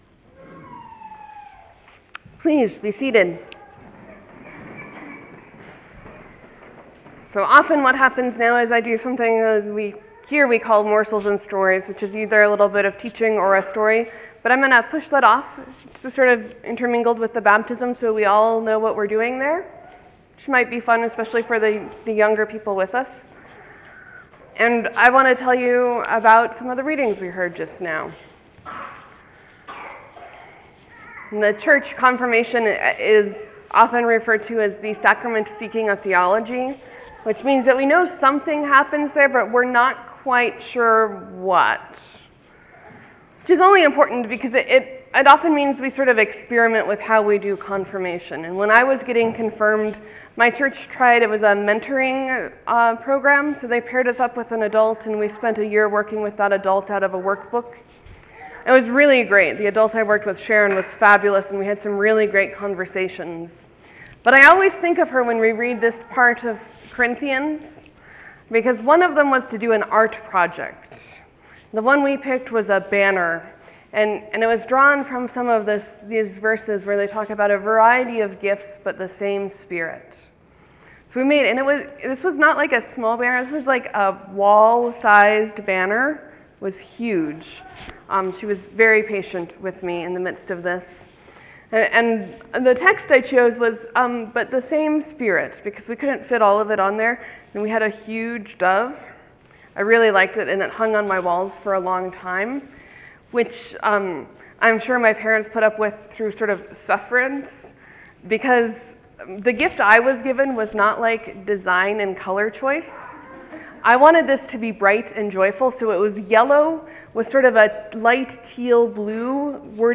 I preached on 1 Corinthians 12:1-11, a passage that has stuck with me since Confirmation, and my poor decorating decisions.